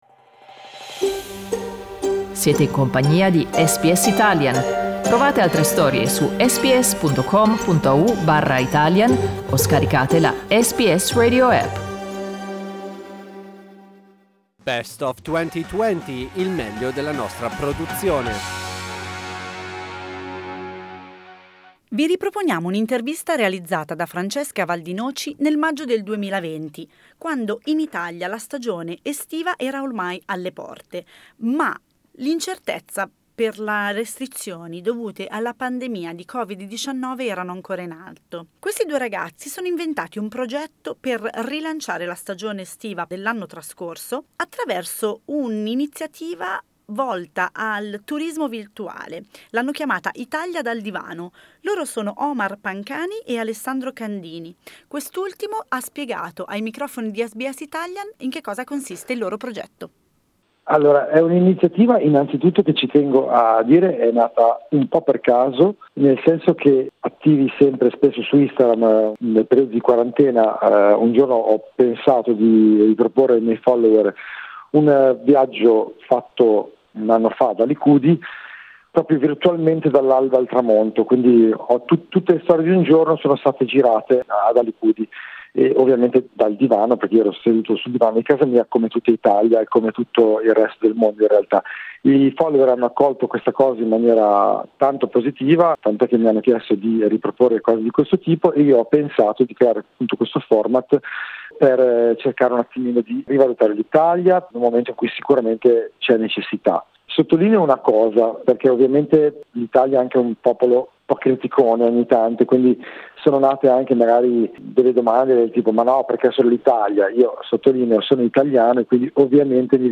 Un'alternativa di viaggio virtuale, comodamente fruibile dal salotto di casa, che due ragazzi italiani si sono inventati per fronteggiare l'incertezza dovuta alle restrizioni da COVID-19. Vi riproponiamo quest'intervista realizzata nel maggio del 2020, volta a rilanciare il turismo online.